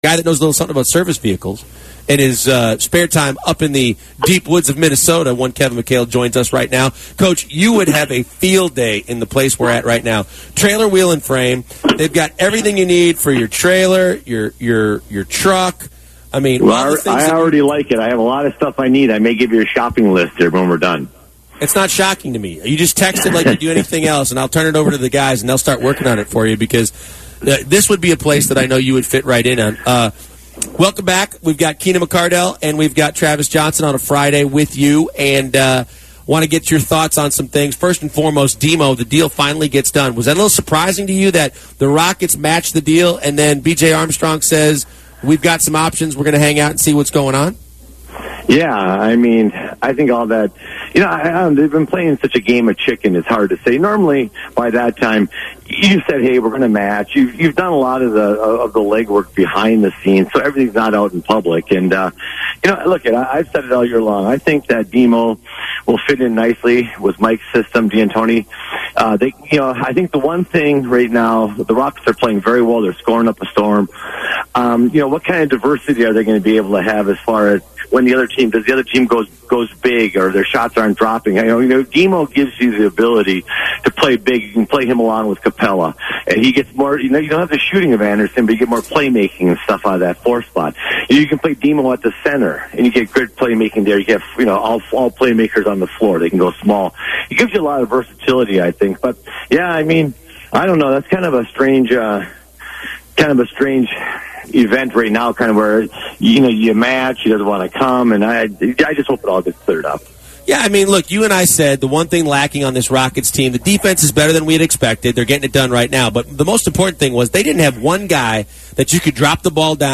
Former Houston Rockets head coch Kevin McHale